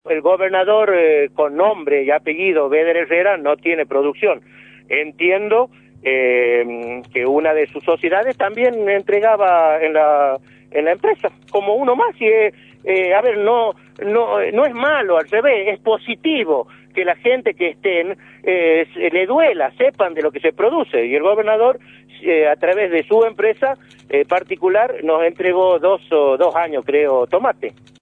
«Tengo una gran sorpresa al escuchar los planteos que hizo el Gobernador, lo que tengo para mostrar es lo que recibí y lo que hice. Yo estuve en esa gestión pero no por ser radical, quiero despejar cualquier tema que haya sido en nombre del radicalismo, tampoco estuve por una propuesta personal. Surge en junio de 2008 cuando el Gobernador se reúne con CARPA, la Cámara ofrece mi persona para acompañar el proceso que se quería iniciar», comentó en diálogo con Radio La Red.